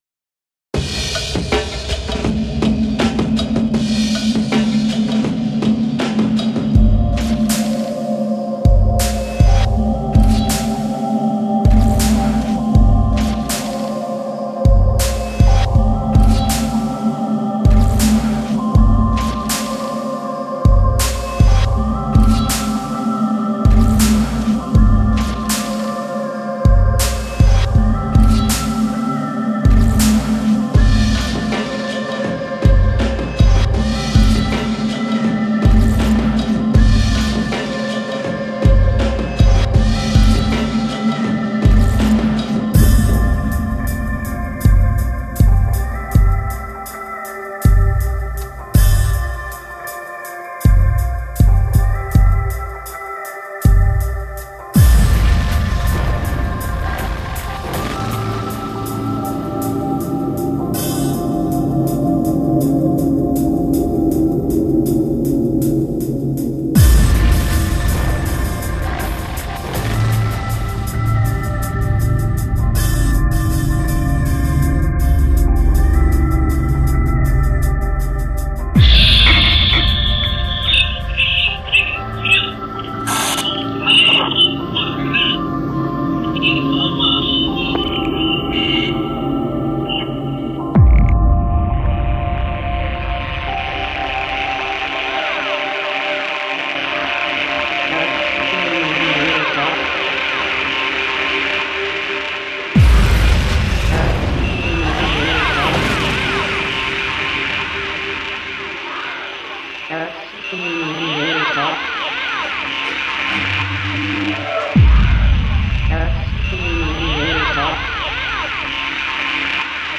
dance/electronic
Leftfield/noise